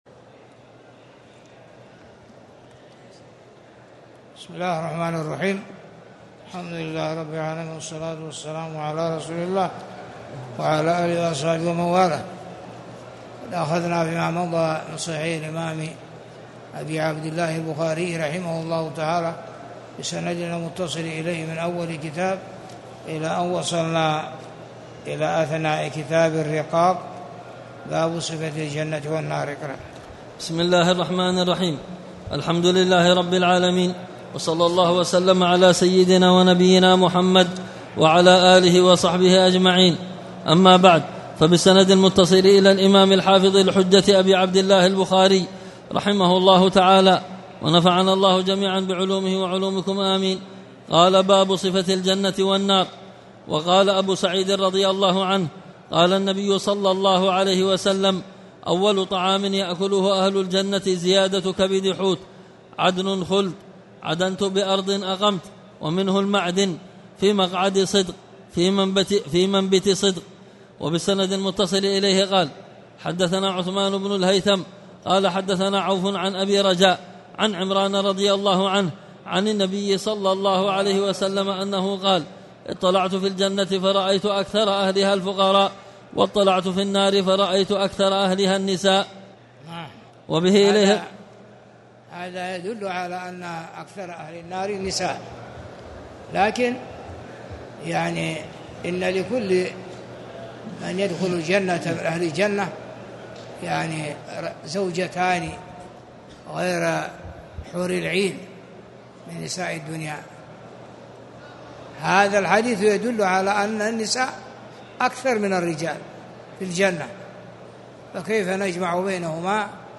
تاريخ النشر ٧ ذو القعدة ١٤٣٨ هـ المكان: المسجد الحرام الشيخ